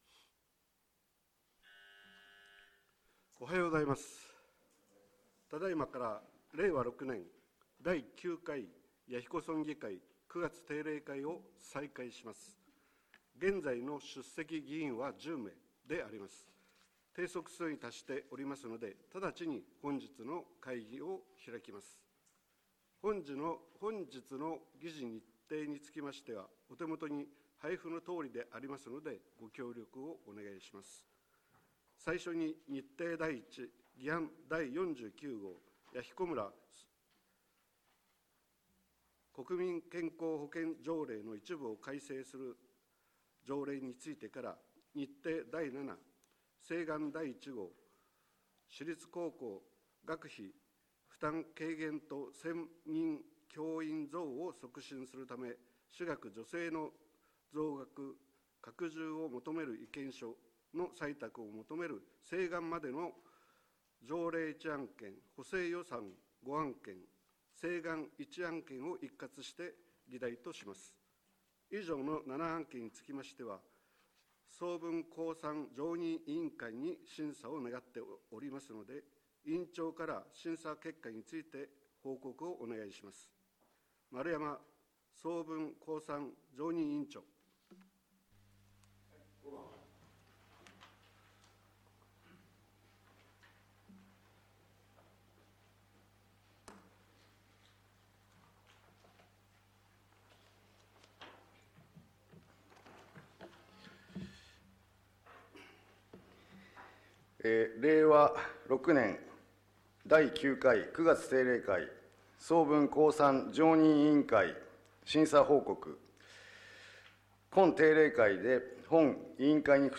本会議